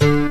neptunesguitar5.wav